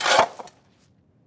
dig2.wav